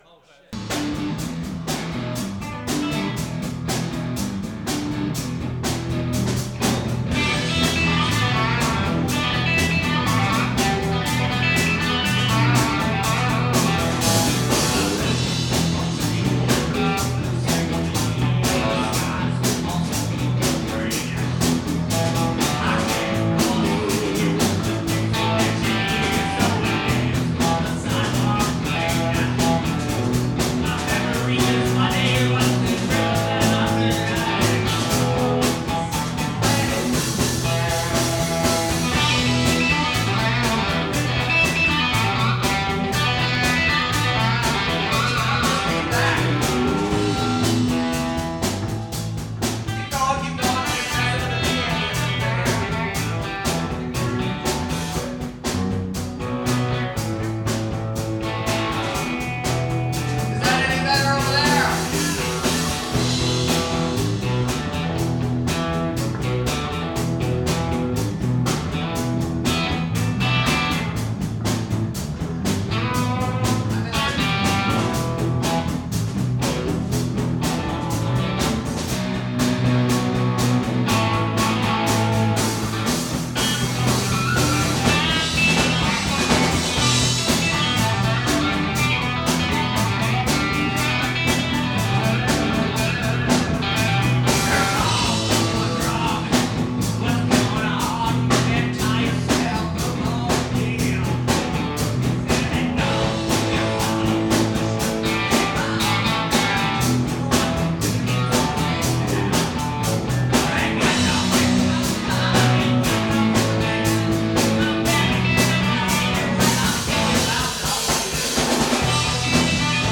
A bunch of friends got together again to make some noise.